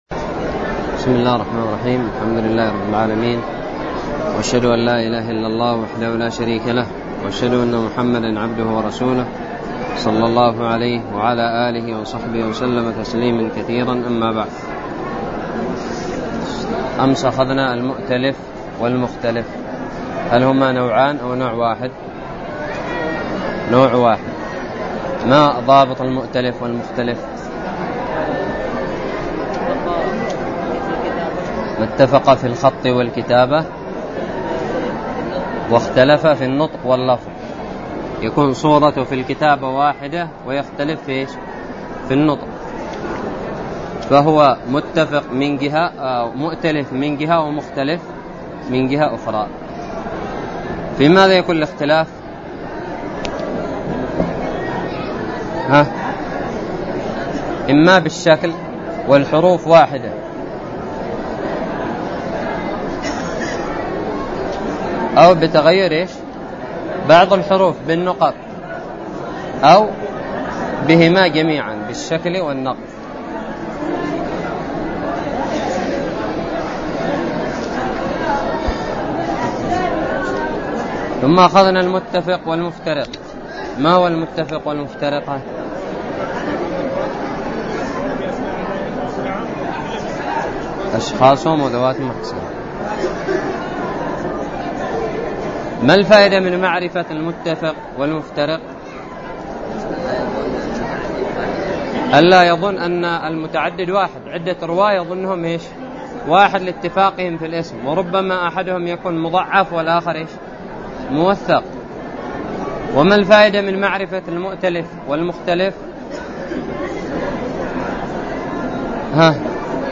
الدرس الخامس والخمسون من شرح كتاب الباعث الحثيث
ألقيت بدار الحديث السلفية للعلوم الشرعية بالضالع